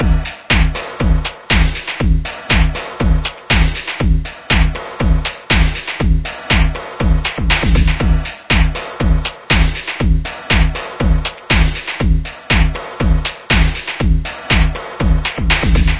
描述：一个带效果的人声样本"请调高音乐quot。
标签： 120 bpm Techno Loops Vocal Loops 568.52 KB wav Key : Unknown
声道立体声